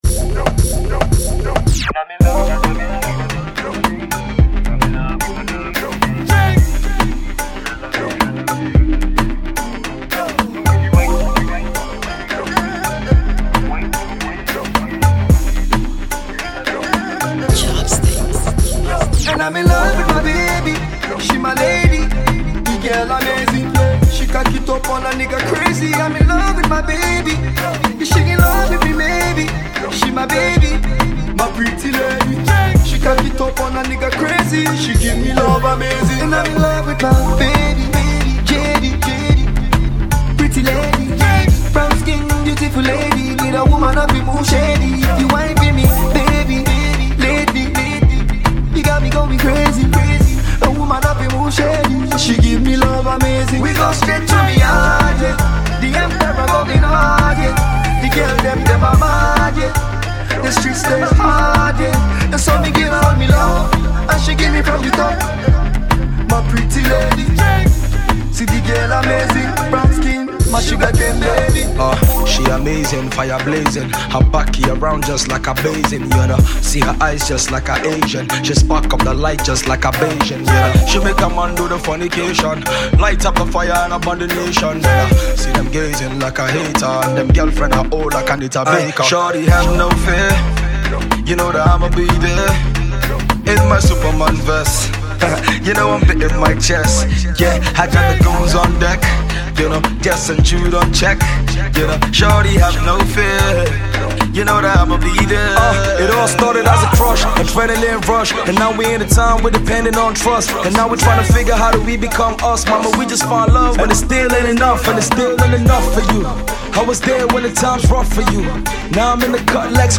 This mid-tempo infectious tune exemplifies this.